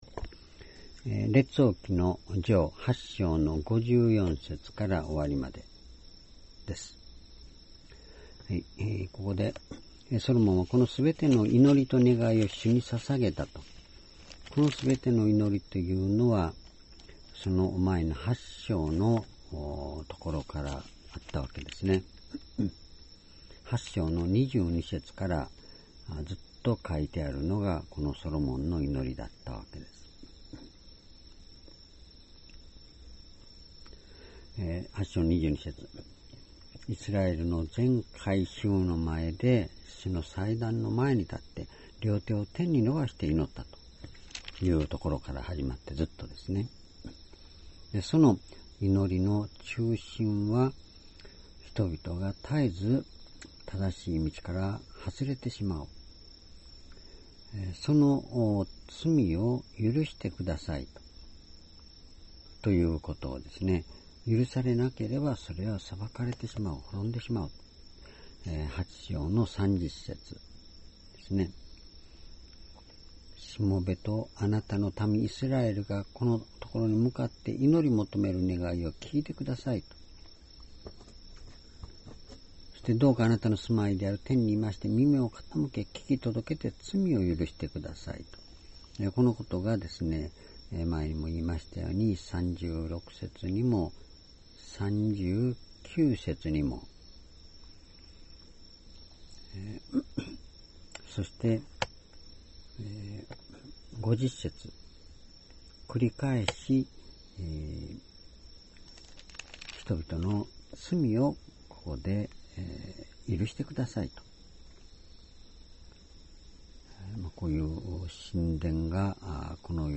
主日礼拝日時 ２０１６年９月４日 聖書講話箇所 列王記上 8章54-61 ｢ソロモンの祈り、祝福｣ ※視聴できない場合は をクリックしてください。